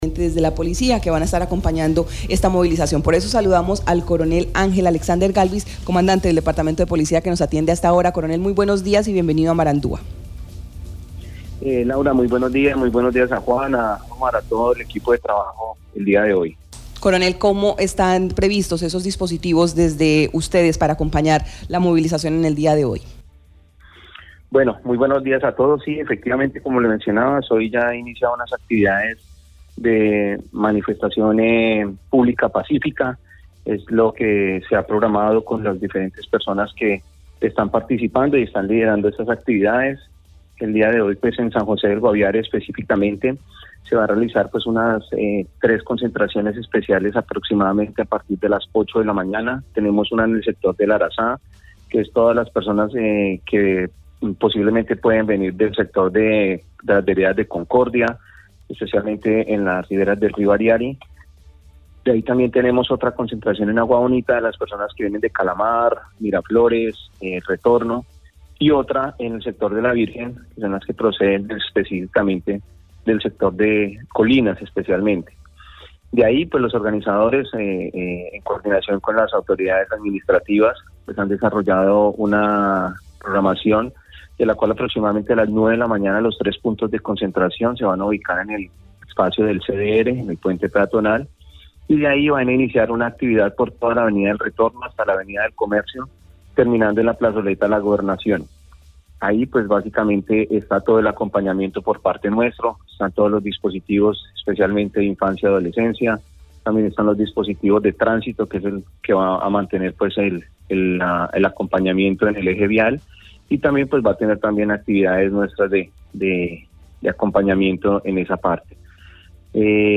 Escuche a coronel Ángel Alexander Galvis Ballén, comandante Departamento de Policía Guaviare.